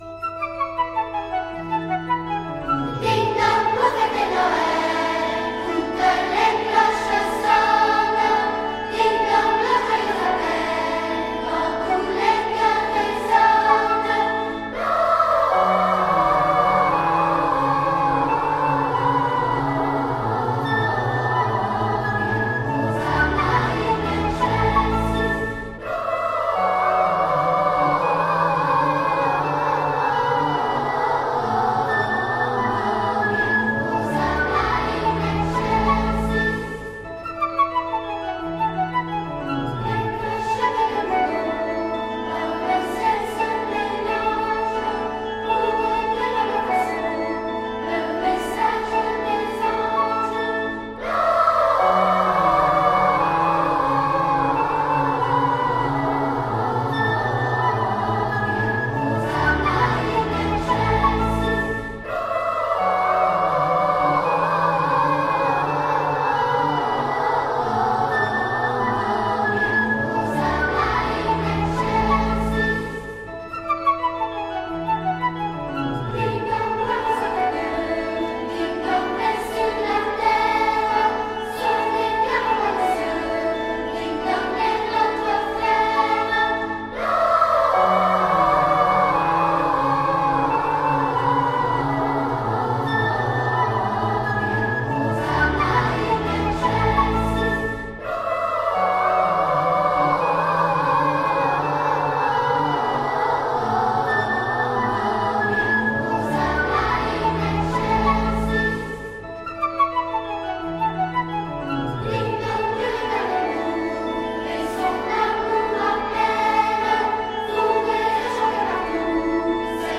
Cloches.mp3